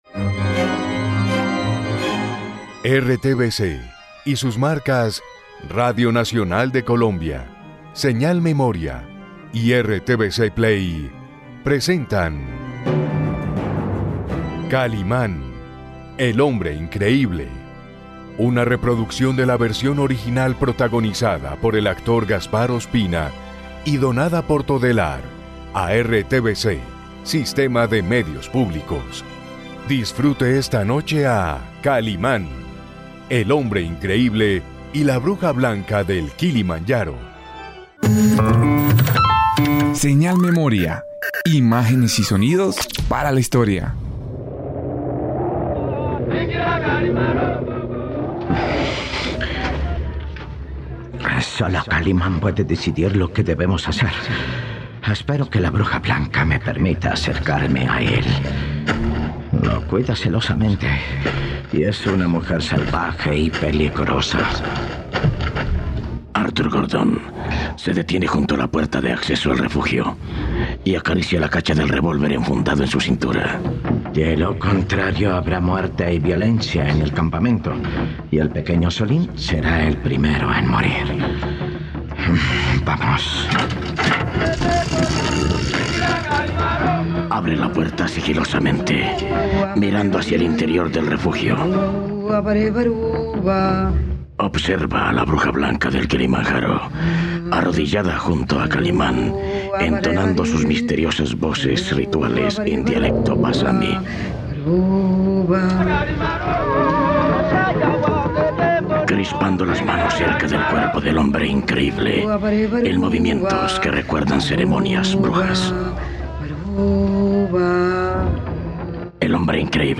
Escucha completa la radionovela de Kalimán y la bruja blanca del Kilimanjaro - capítulos gratis en RTVCPlay
Kaliman Bruja Blanca Cap 153 Con Promos.mp3